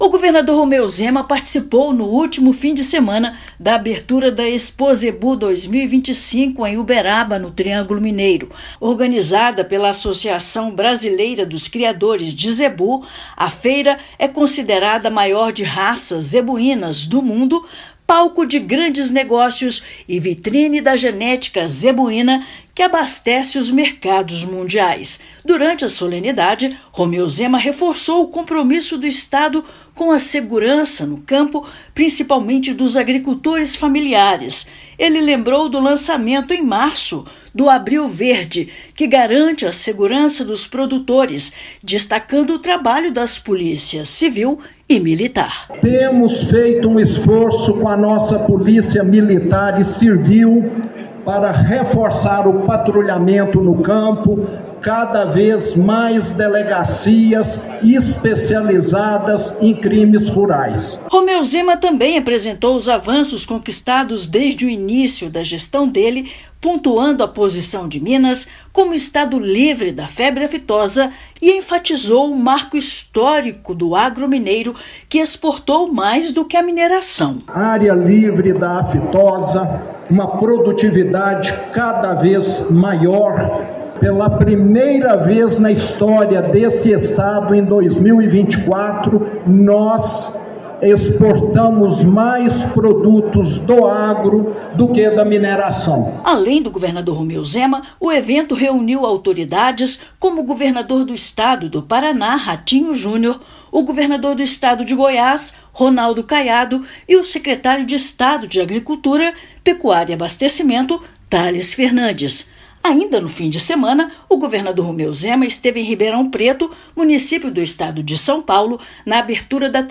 Governador também destacou a força do agronegócio no estado, que pela primeira vez, superou a mineração nas exportações. Ouça matéria de rádio.